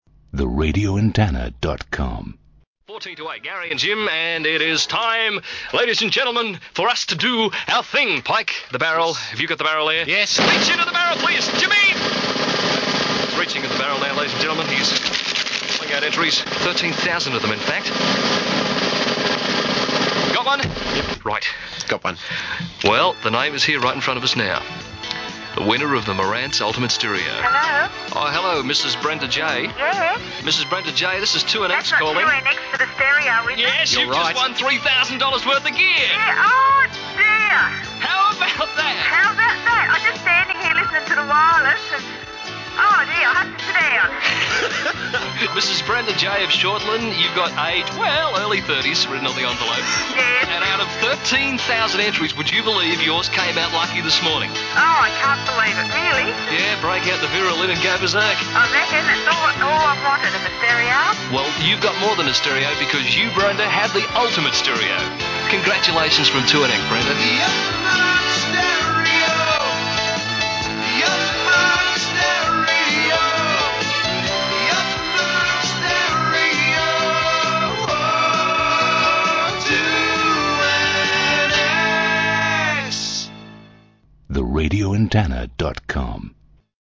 RA Aircheck – 2NX Ultimate Stereo Winner 1975
RA-Aircheck-2NX-Ultimate-Stereo-Winner-1975.mp3